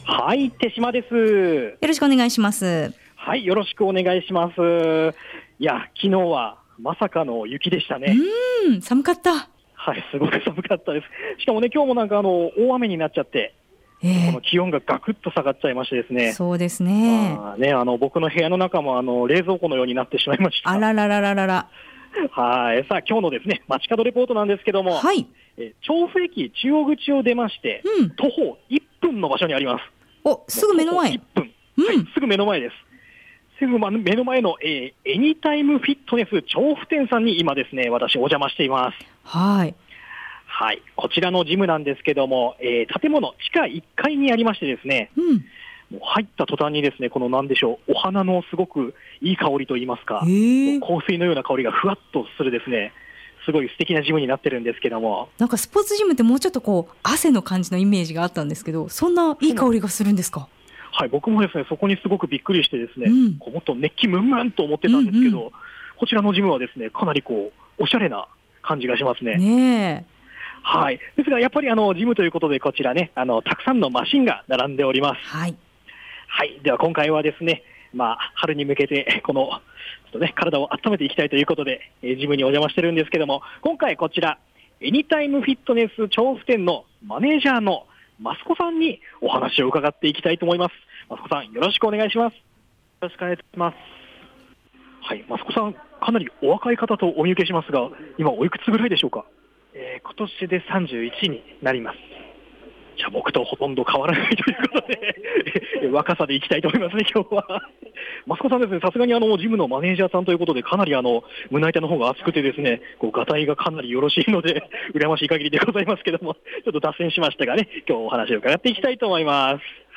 午後のカフェテラス 街角レポート
今回の街角レポートは２４時間年中無休で営業中の、 「エニタイムフィットネス 調布店」さんにお邪魔しました […]